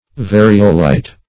Search Result for " variolite" : The Collaborative International Dictionary of English v.0.48: Variolite \Va"ri*o*lite\, n. [L. varius various + -lite: cf. F. variolite.]